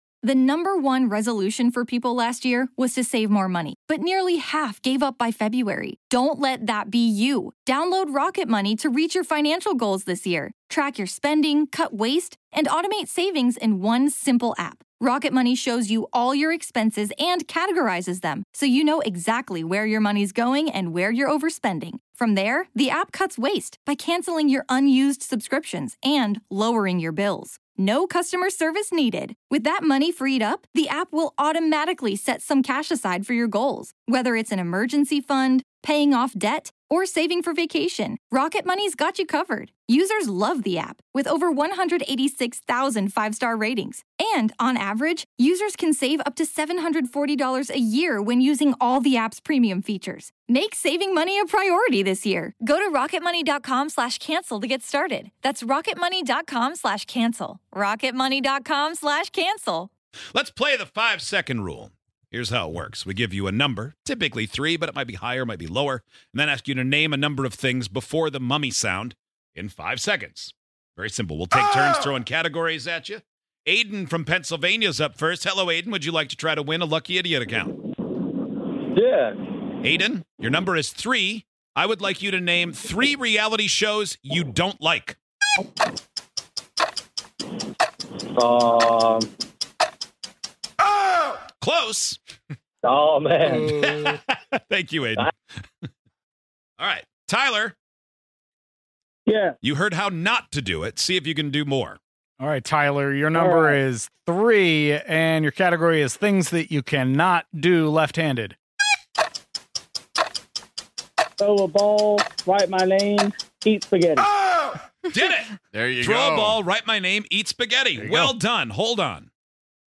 Give this game a try and see if you can answer before the mummy sound!